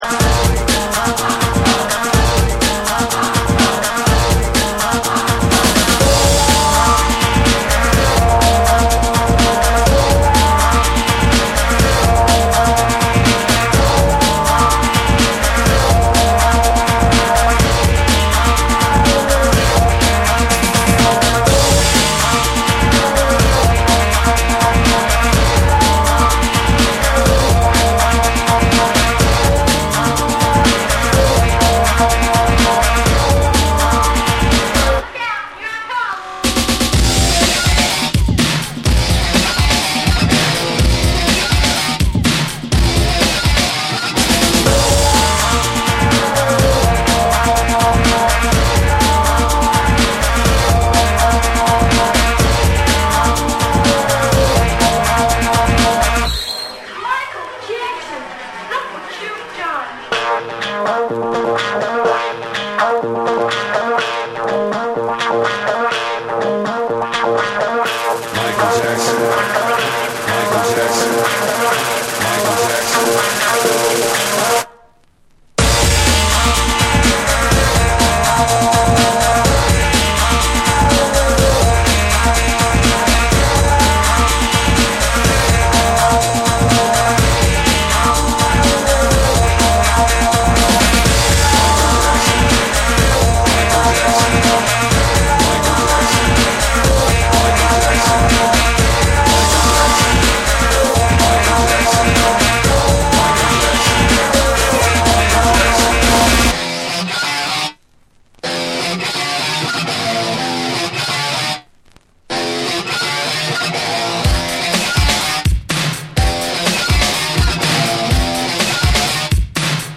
ファンキーなベースラインとスネア強めのブレイクビーツにロッキンなギターも登場する